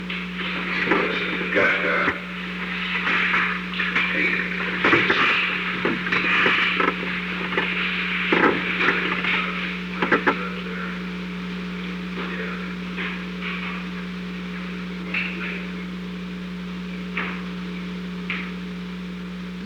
Secret White House Tapes
Conversation No. 538-2
Location: Oval Office
The President met with an unknown person